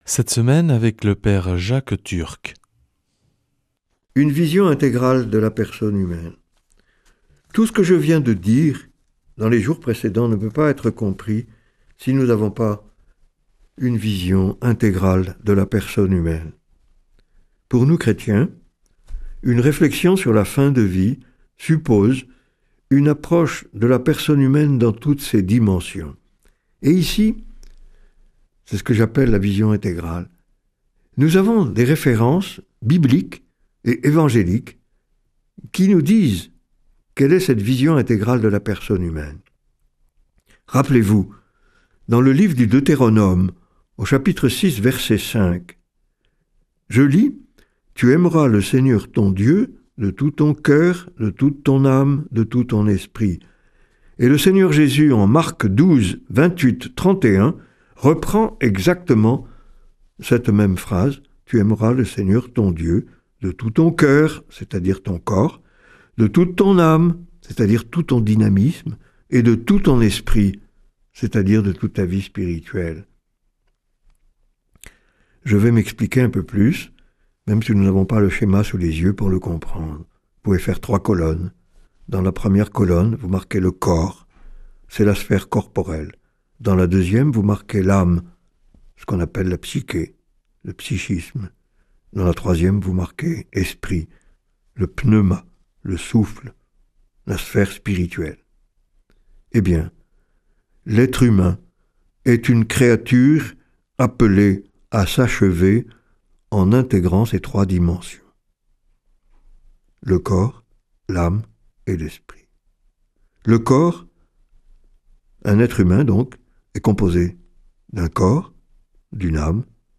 jeudi 26 février 2026 Enseignement Marial Durée 10 min
Une émission présentée par